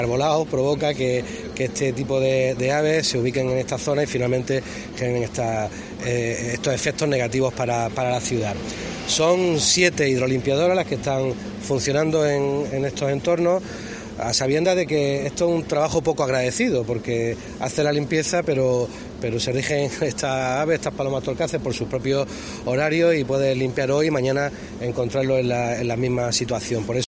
Jacobo Calvo explica el plan especial de limpieza de excrementos de aves